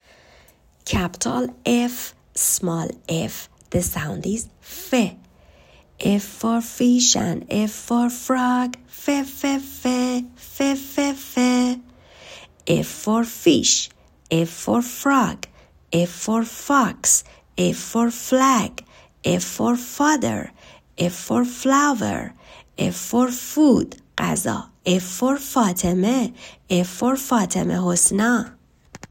حروفی که بچه ها یاد گرفتند تا اینجا رو در قالب چند ویس ، گذاشتم.
حرف Ff، صداش و لغاتش